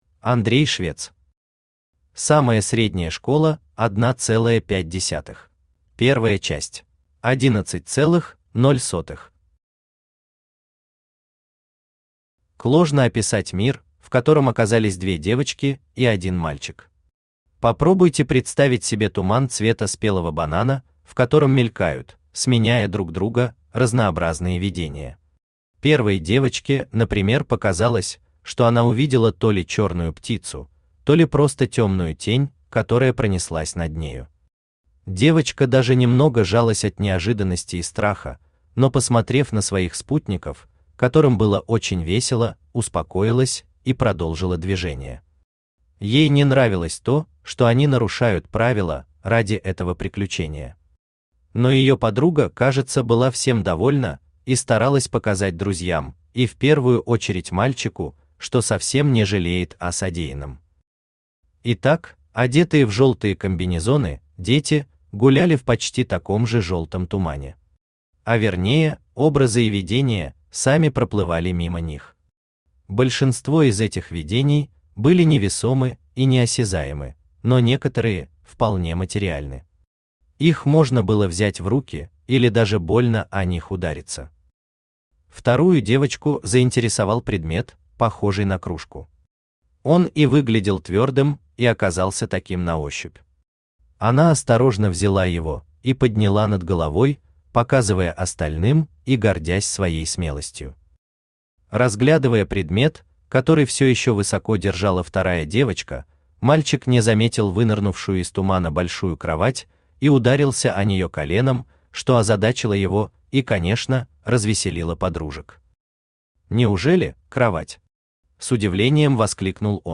Аудиокнига Самая средняя школа 1.5 | Библиотека аудиокниг
Aудиокнига Самая средняя школа 1.5 Автор Андрей Владимирович Швец Читает аудиокнигу Авточтец ЛитРес.